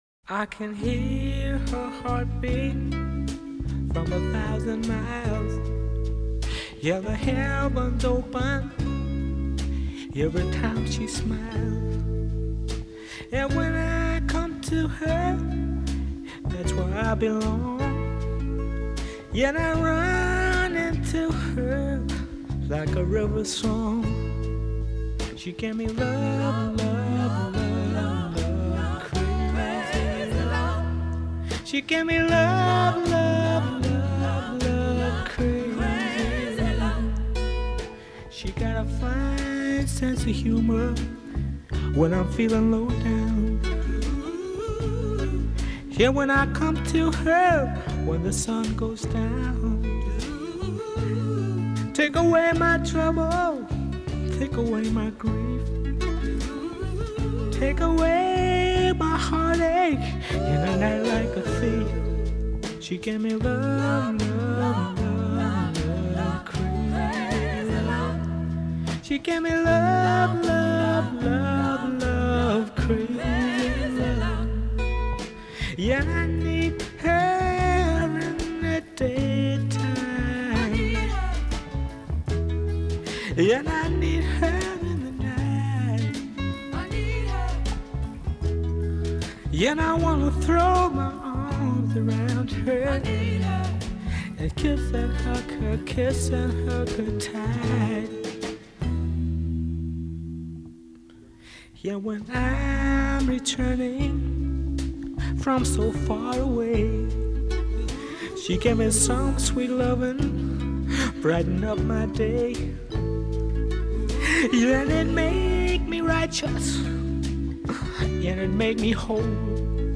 The blissful instrumental arrangement
cooing falsetto and earnest lyrics